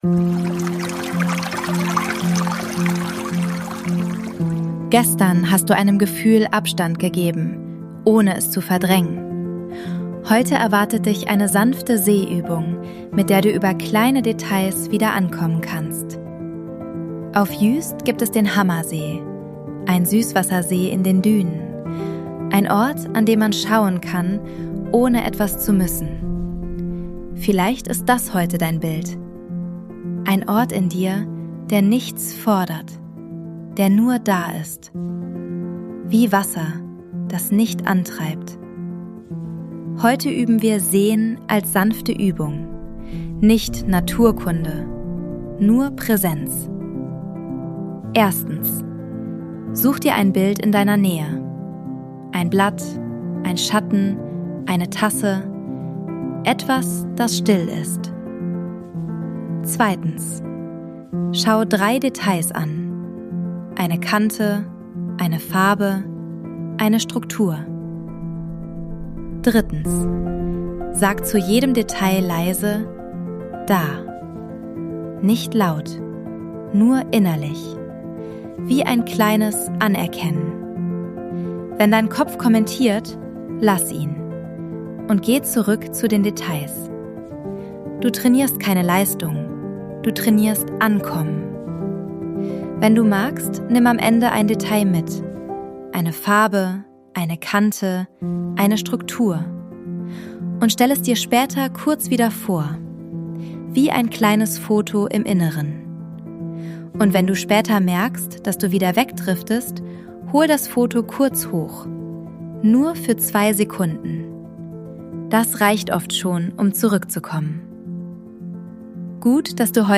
Sounds & Mix: ElevenLabs und eigene Atmos